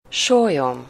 Ääntäminen
Synonyymit hök Ääntäminen Tuntematon aksentti: IPA: /falk/ Haettu sana löytyi näillä lähdekielillä: ruotsi Käännös Ääninäyte 1. sólyom 2. vércse Artikkeli: en .